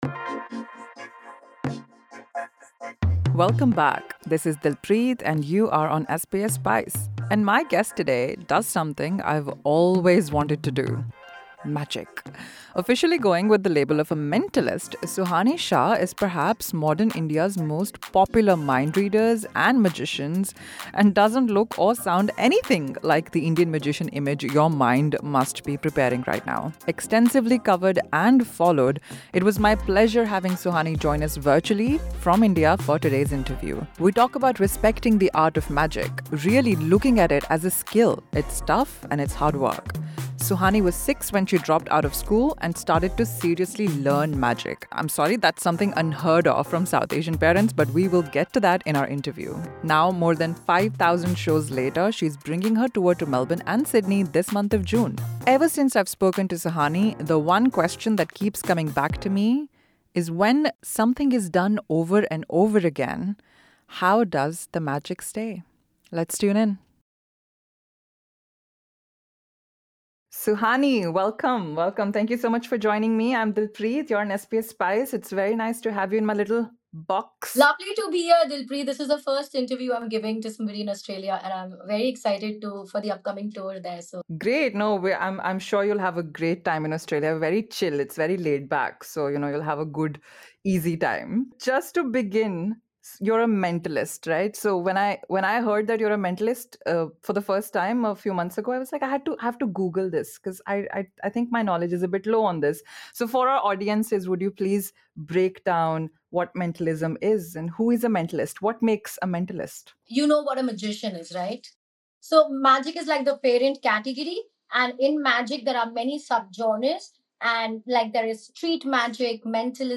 Officially known as a mentalist, Suhani Shah is arguably one of modern India's most popular mind readers and magicians. Extensively covered and followed, SBS Spice hosts her virtually from India to explore what lies behind the ancient artform - especially given the digital world's consumption.